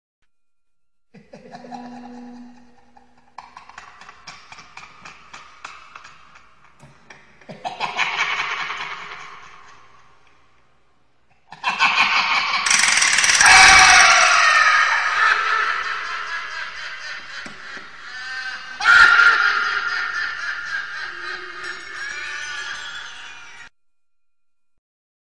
دانلود آهنگ خنده شیطانی 1 از افکت صوتی انسان و موجودات زنده
جلوه های صوتی
دانلود صدای خنده شیطانی 1 از ساعد نیوز با لینک مستقیم و کیفیت بالا